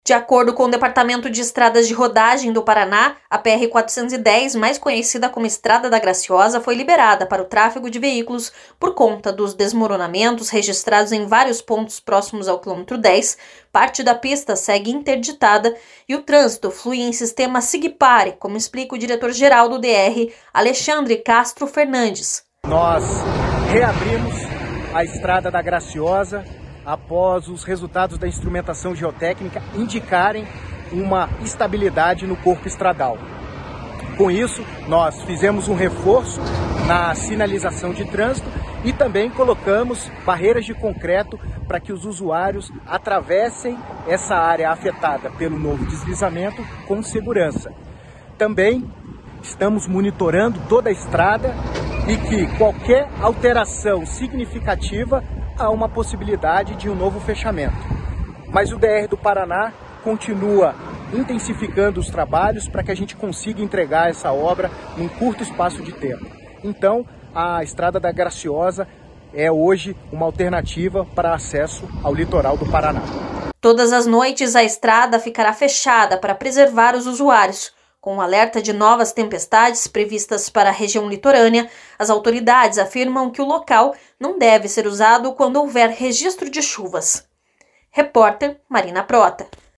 Por conta dos desmoronamentos registrados em vários pontos próximos ao km 10, parte da pista segue interditada e o trânsito flui em sistema siga e pare, como explica o diretor-geral do DER/PR, Alexandre Castro Fernandes.